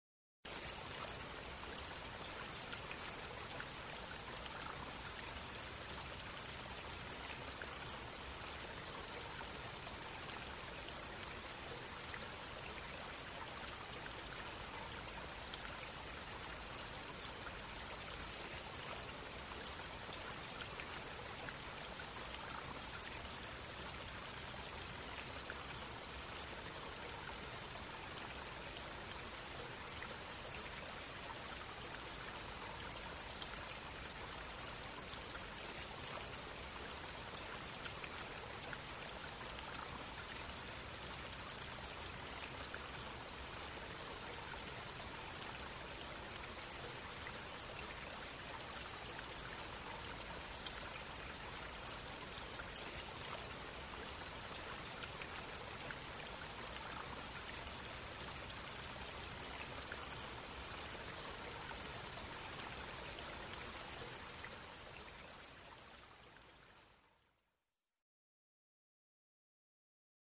Babbling brook
Sound wellness from Toggenburg Tourism.